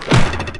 Hit.wav